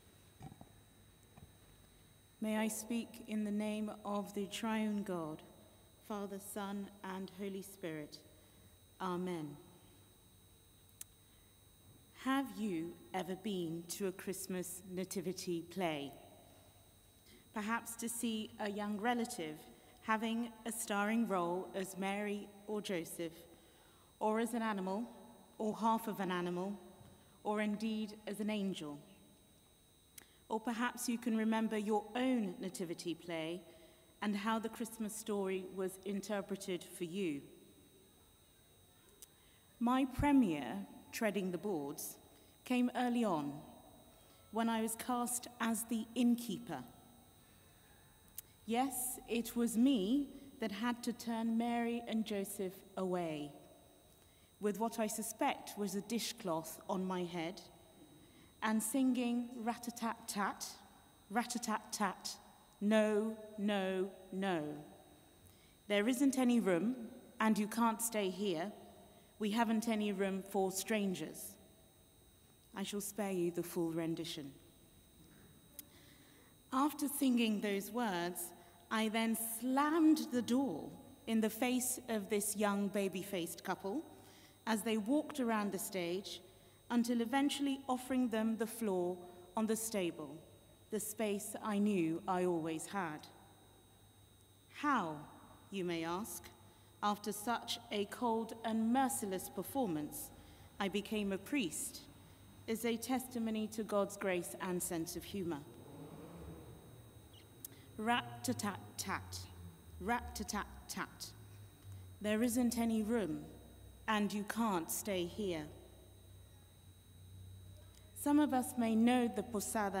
Festival of Lessons & Carols – Sunday 19th December 2021